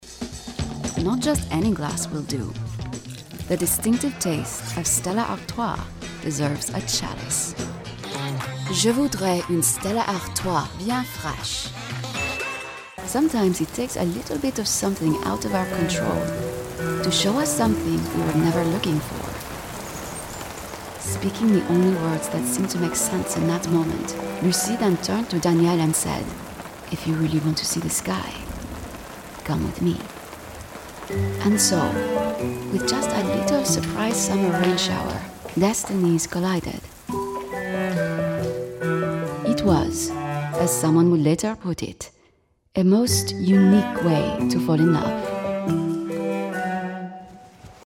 From witty and wry to warm and compassionate, I've got you.
French Accent Demo